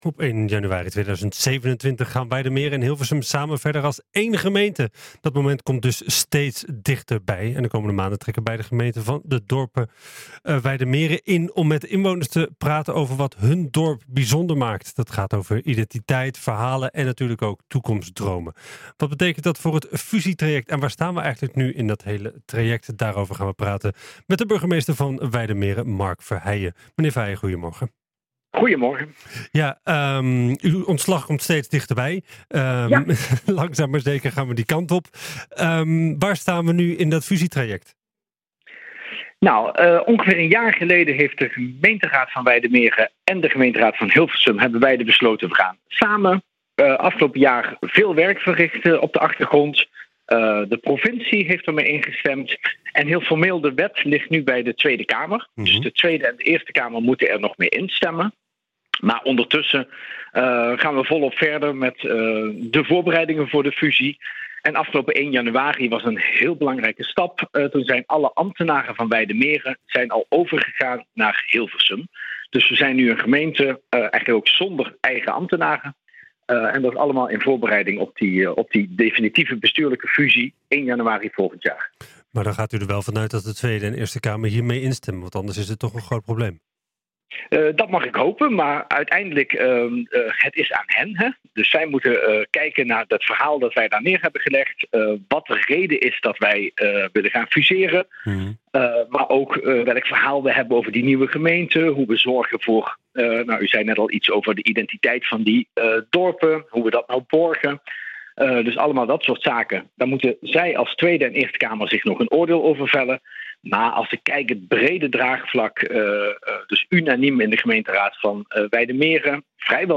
Daarover praten we met Mark Verheijen, burgemeester van Wijdemeren.